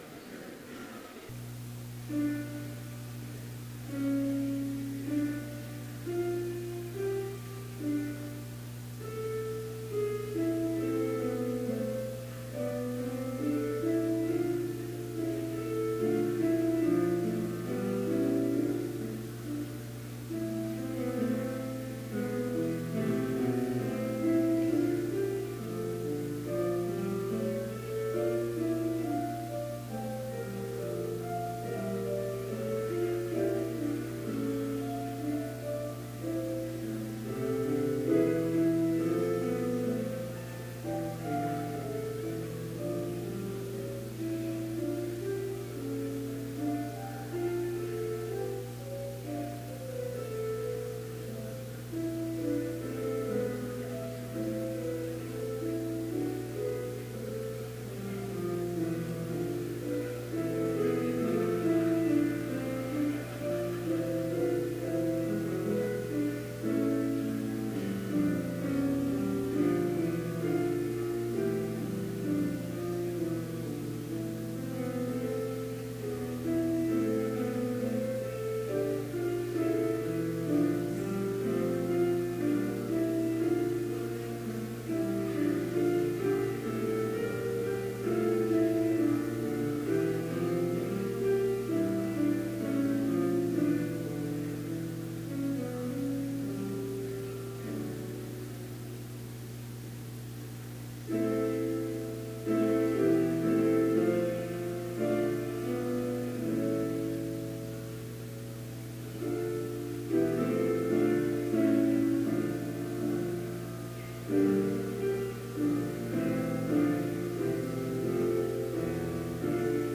Complete service audio for Chapel - April 21, 2017